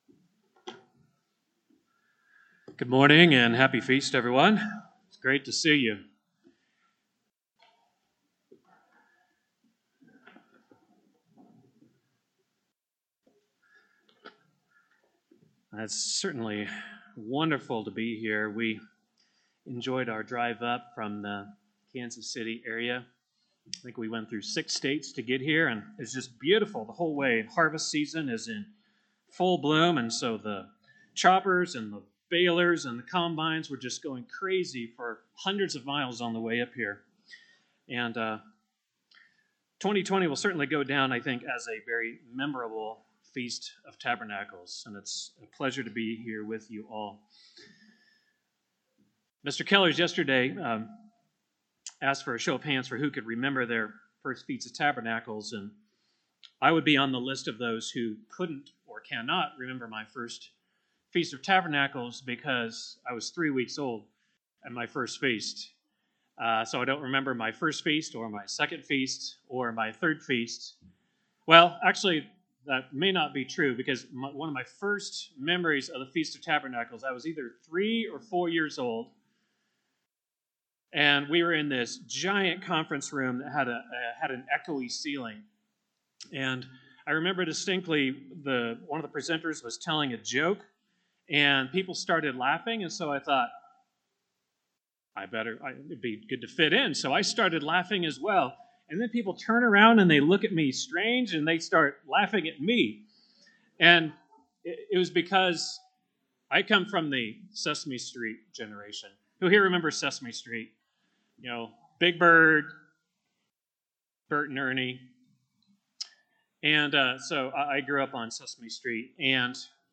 Split sermon during the Feast of Tabernacles in Glacier Country, Montana, 2020
This sermon was given at the Glacier Country, Montana 2020 Feast site.